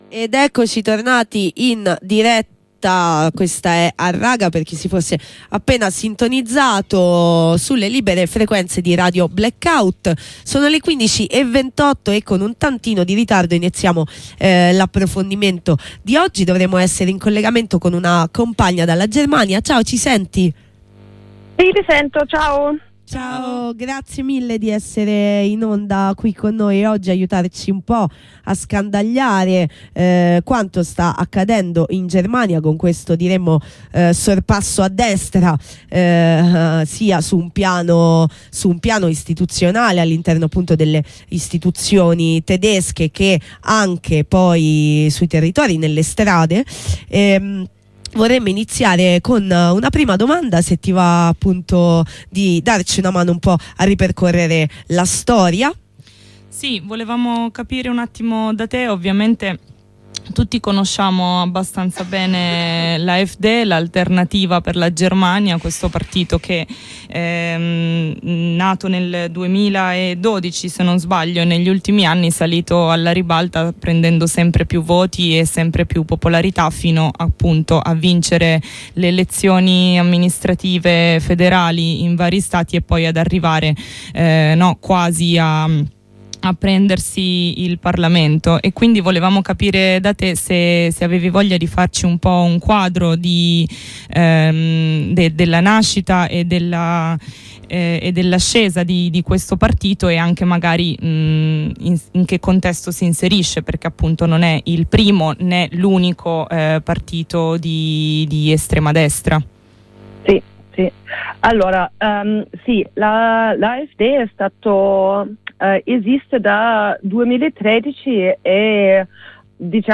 In questa puntata di Harraga, in onda su Radio Blackout, abbiamo continuato la serie di approfondimenti sulle estreme destre con un collegamento dalla Germania, dove il fascismo sembra avanzare inarrestabile su tutti i fronti.